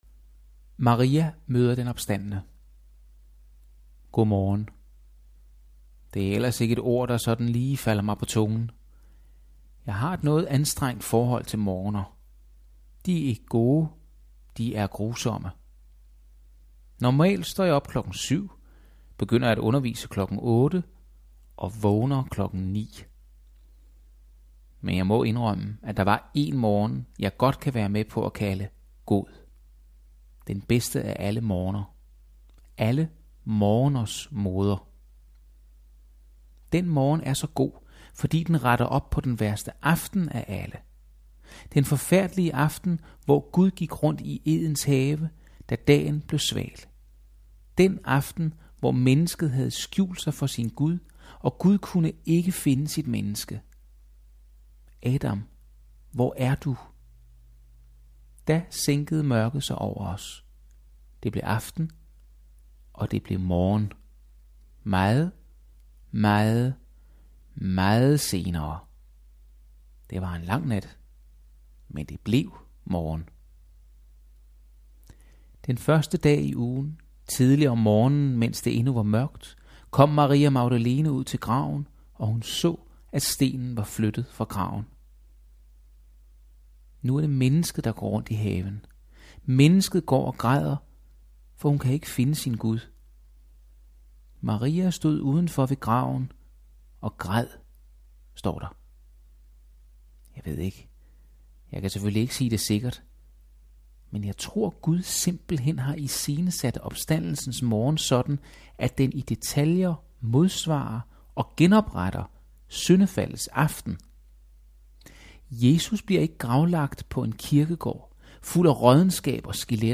Lydbog